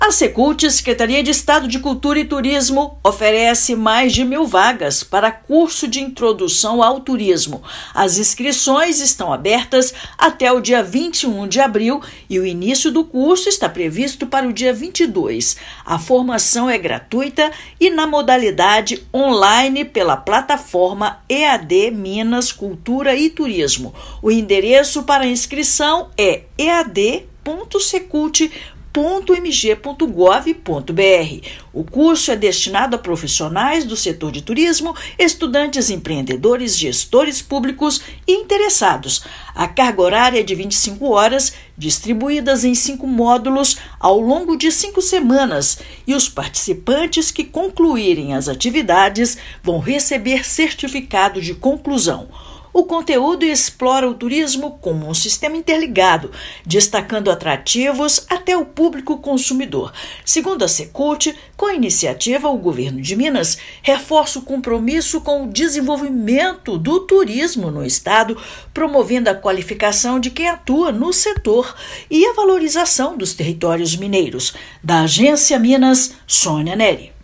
Inscrições vão até o dia 21/4, por meio da plataforma EaD Minas Cultura e Turismo. Ouça matéria de rádio.